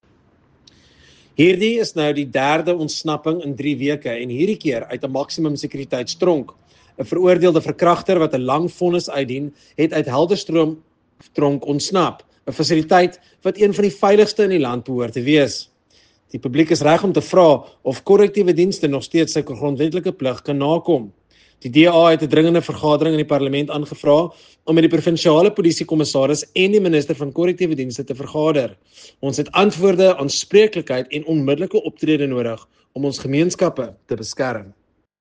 Afrikaans soundbites by Nicholas Gotsell MP.